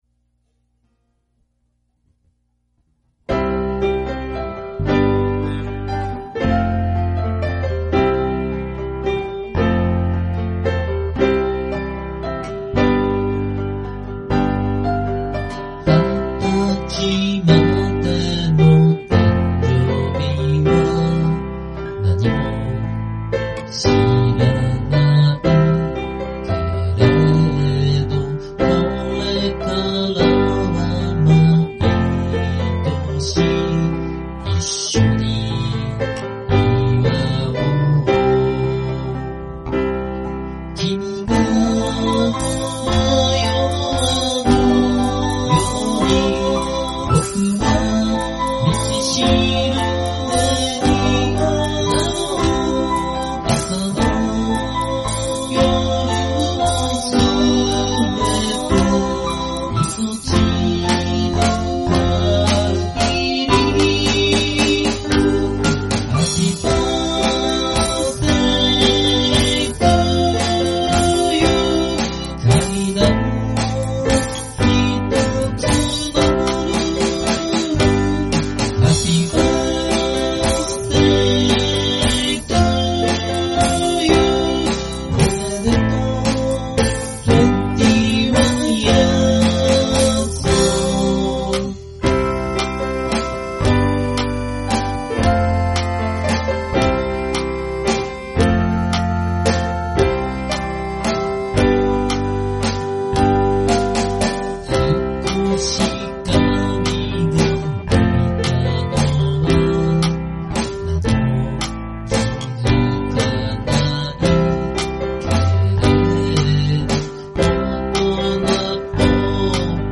Vocal,Chorus,A.guitar,Bass,Keyboard,Drums
バースデイソングを一度作りたかった。